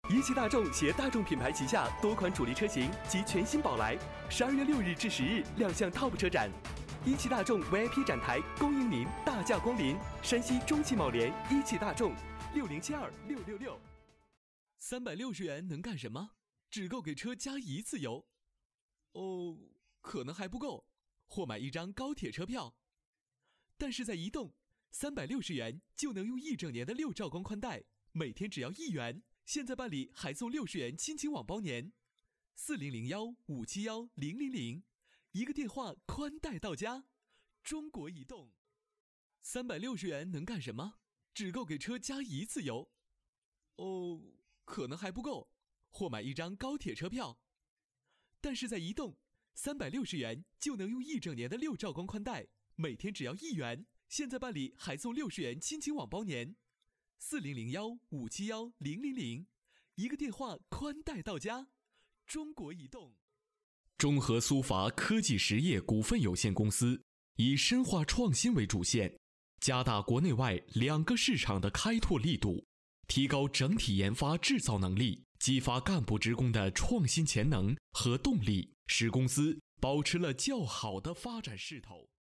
Männlich